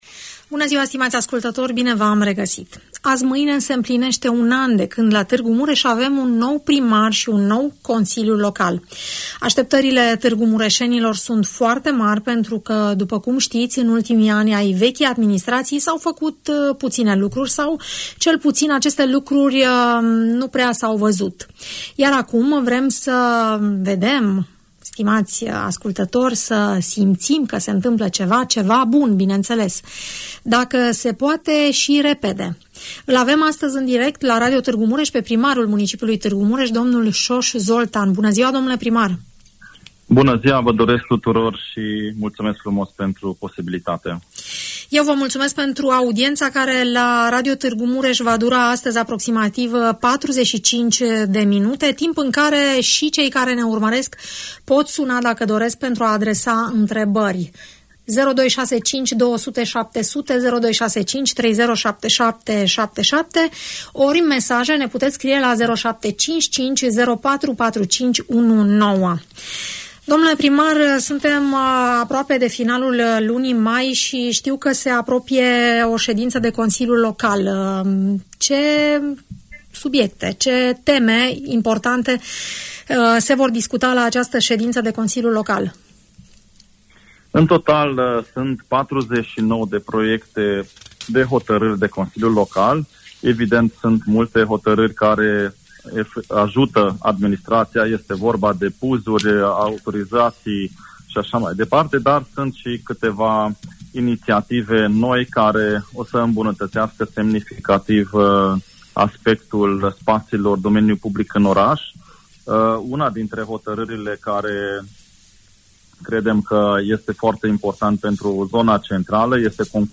Primarul municipiului Tg Mureș, dl Soos Zoltan, vorbește la Radio Tg Mureș despre marile proiecte ale orașului, despre lucrările în derulare, dar și despre ceea ce urmează să facă în perioada următoare.